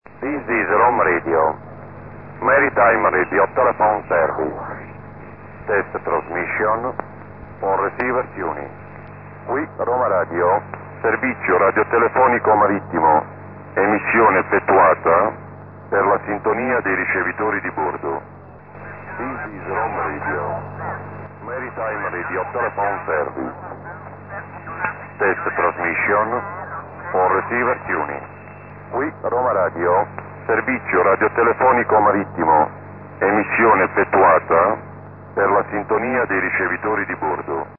very often in the loudspeakers at that time: Roma Radio with voice mirror